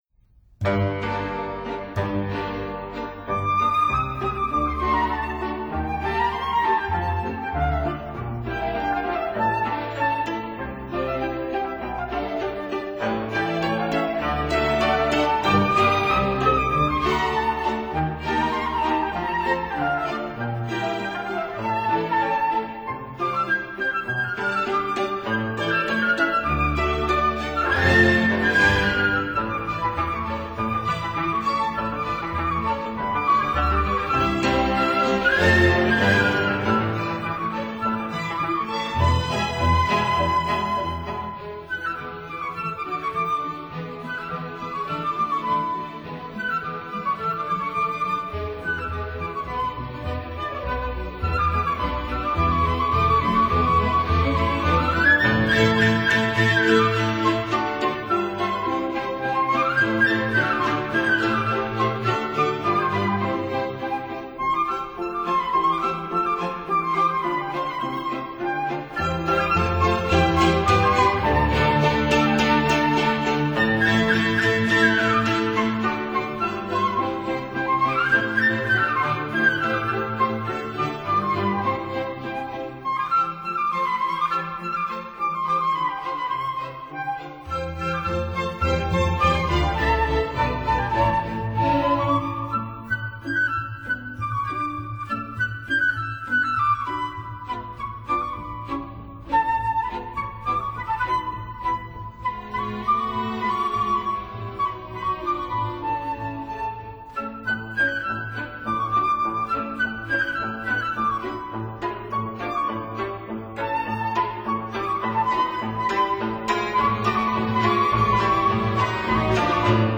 for flute and string orchestra
for soprano and string orchestra
for harpsichord and string orchestra
cimbalom